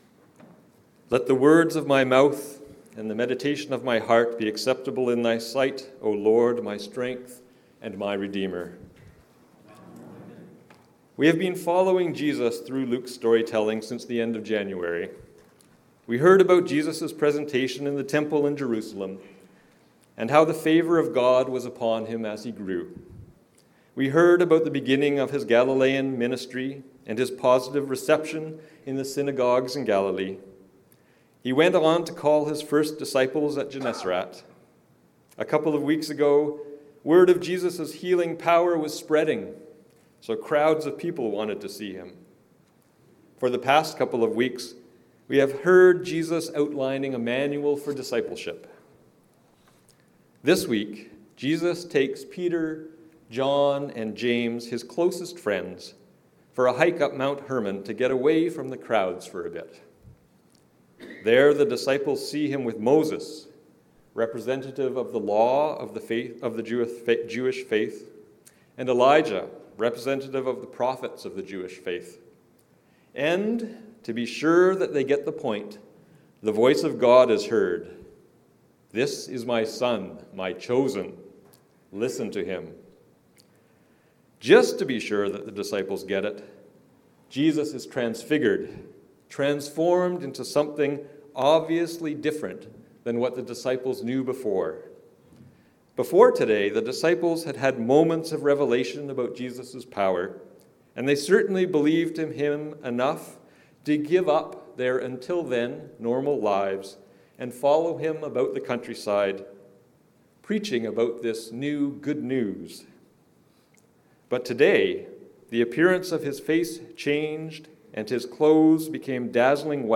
Listen to Jesus. A sermon on Luke 9:28-36